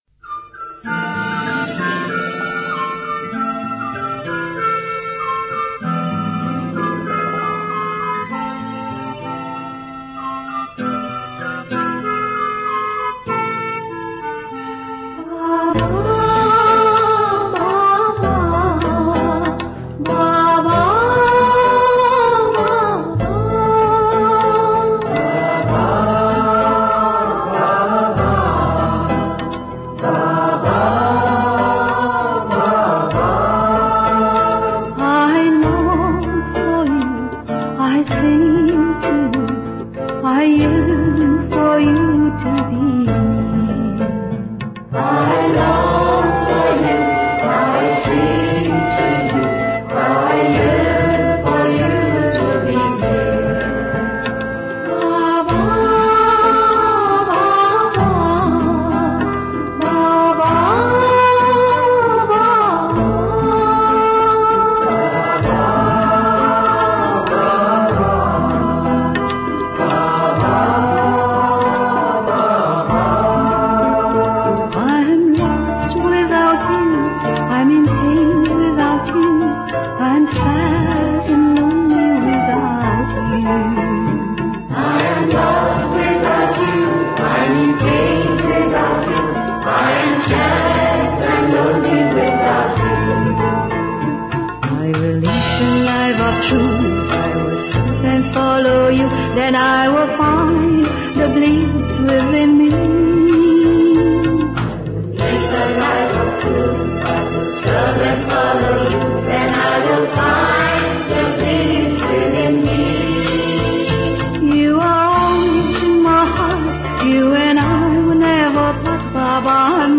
1. Devotional Songs
Minor (Natabhairavi)
8 Beat / Keherwa / Adi
Medium Slow
2.5 Pancham / E Flat
6.5 Pancham / B Flat
Lowest Note: S / C
Highest Note: R2 / D (higher octave)